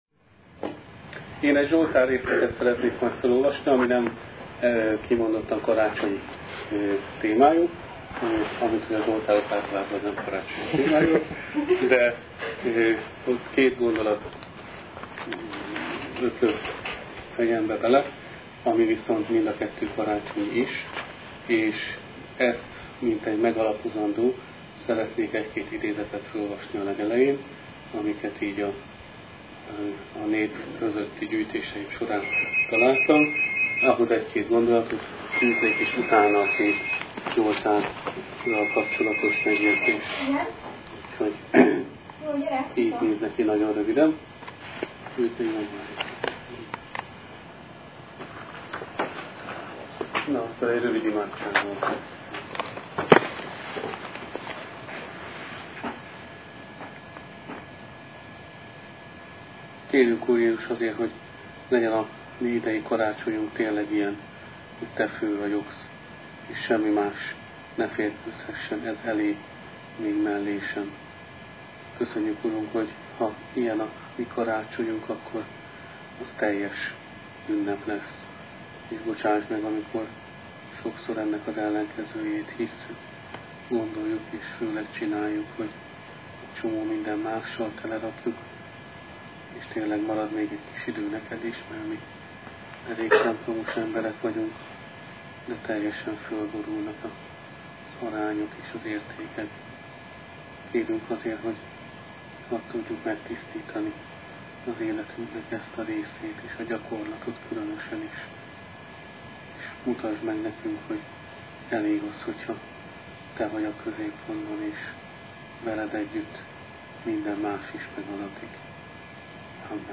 Ifikarácsonyi áhítat